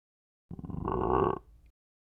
burp.wav